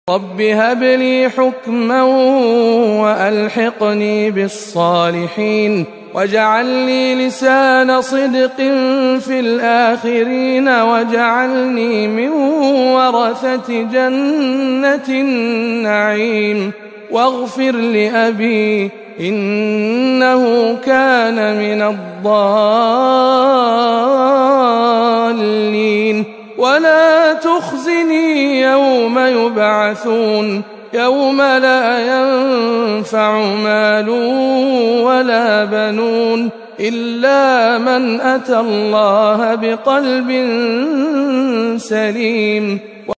تلاوة مميزة من سورة الشعراء